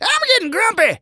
tnt_guy_hurt_03.wav